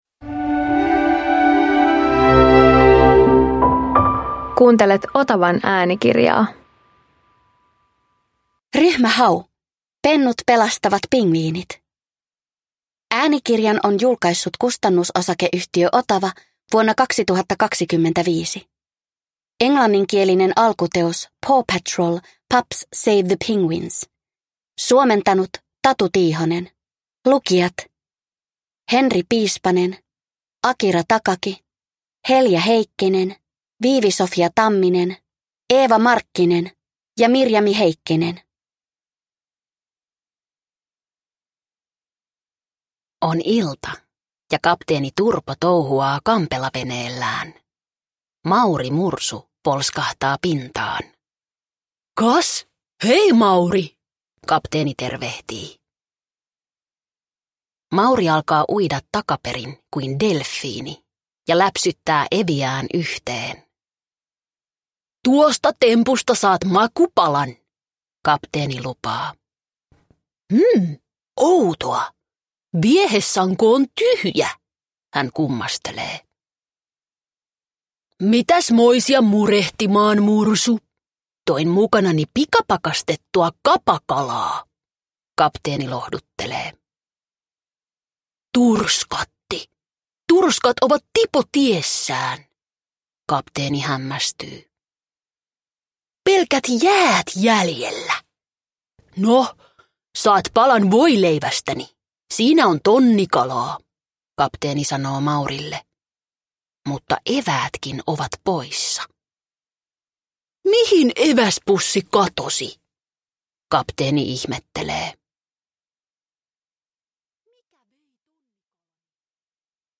Ryhmä Hau - Pennut pelastavat pingviinit – Ljudbok